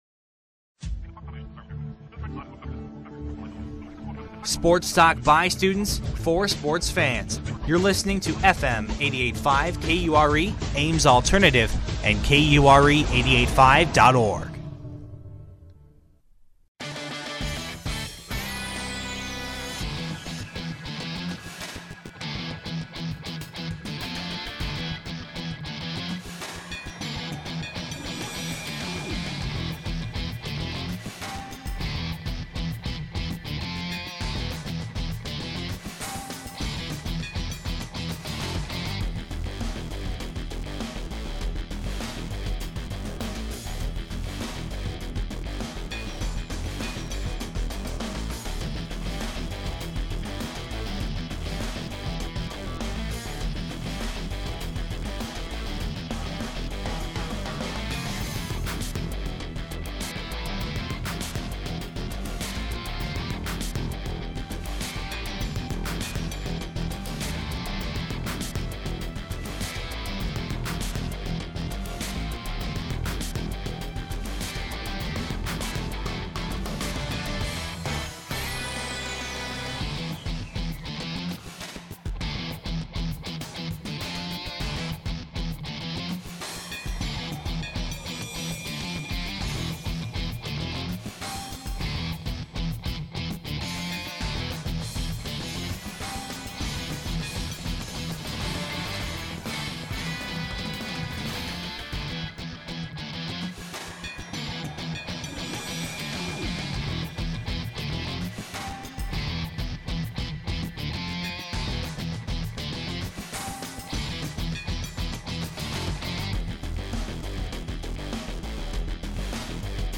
Category: Radio   Right: Personal